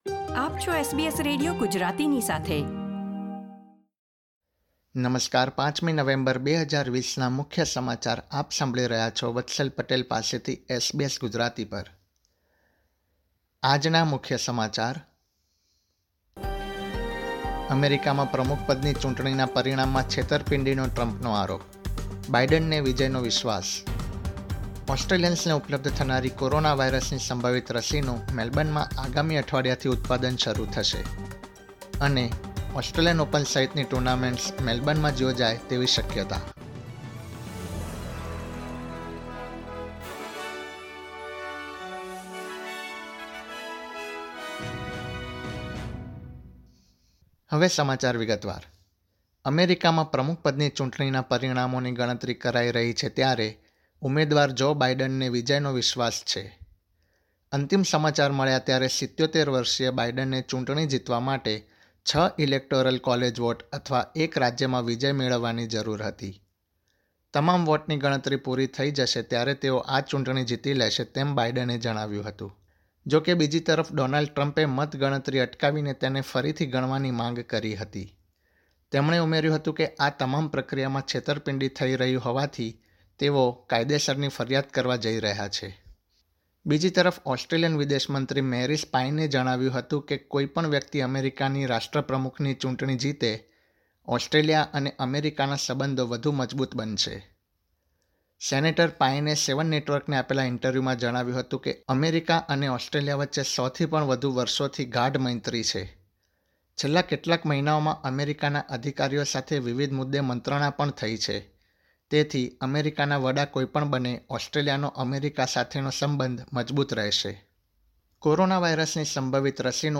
SBS Gujarati News Bulletin 5 November 2020
gujarati_0511_newsbulletin.mp3